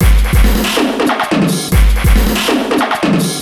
E Kit 11.wav